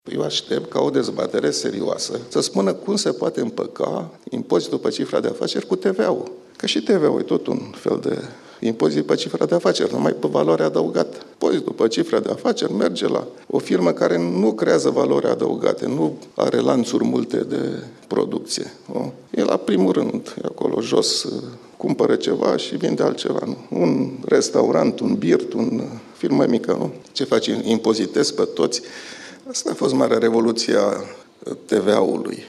Impozitul pe cifra de afaceri poate fi aplicat la o firmă care nu creează valoare adăugată, nu are lanţuri multe de producţie, la un restaurant, birt sau o firmă mică, a declarat, marţi, guvernatorul Băncii Naţionale a României, Mugur Isărescu, în cadrul unei conferințe.